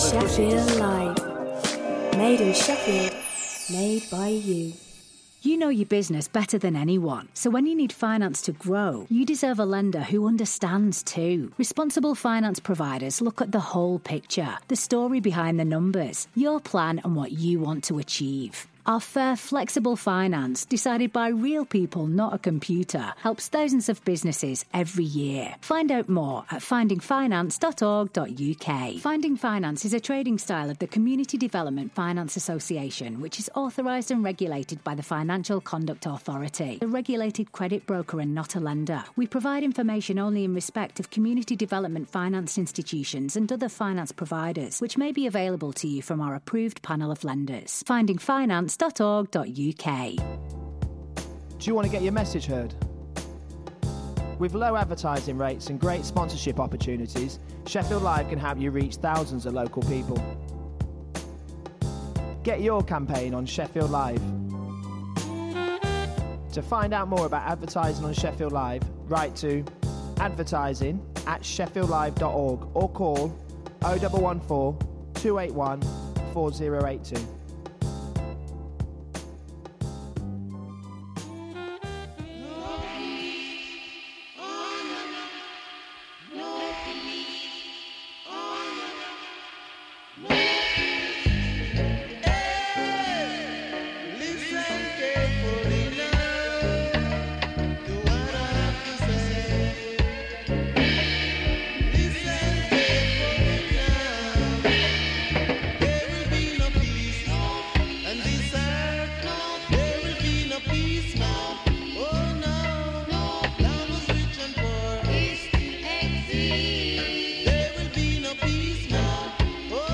Jolly music, news, chat and regular prize giveaways!